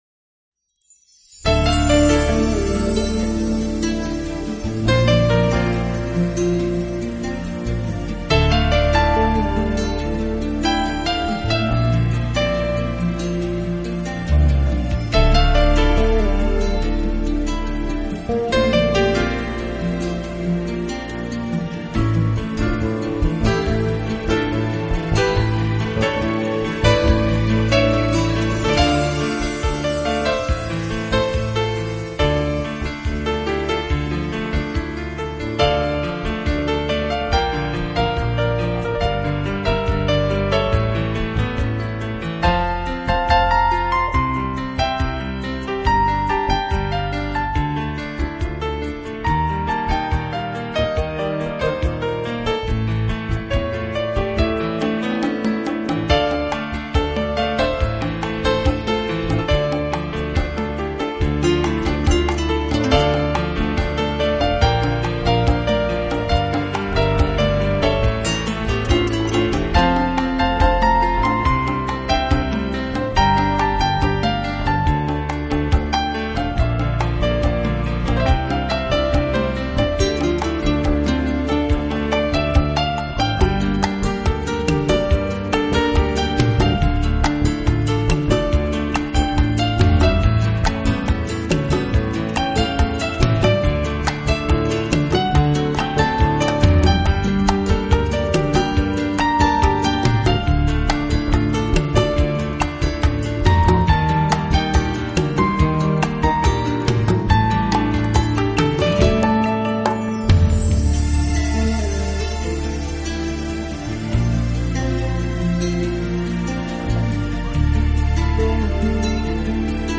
纯音乐
每一声虫鸣，流水都是从大自然中记录的，他们为了采集自然的
合成乐音为演奏的主体，再结合他们所采撷的大自然音效，在这两相结合之下，您可
器配置，使每首曲子都呈现出清新的自然气息。